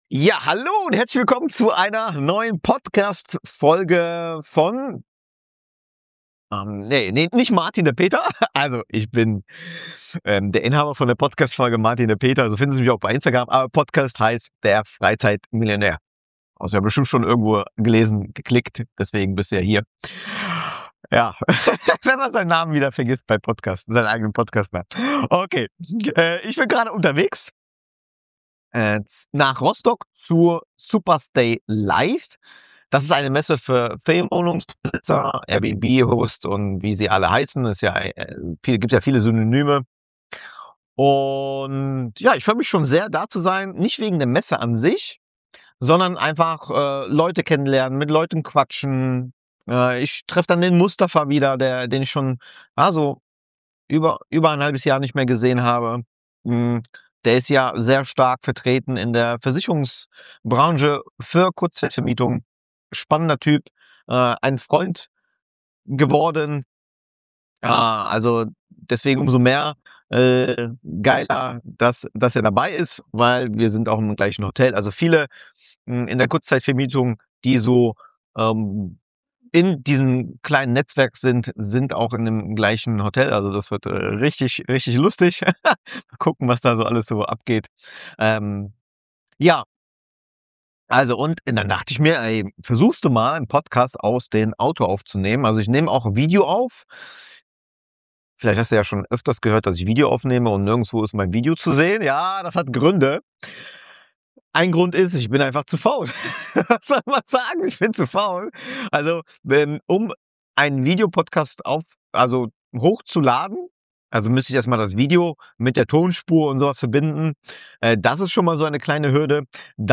Diese Folge entsteht unterwegs, im Auto, auf dem Weg nach Rostock.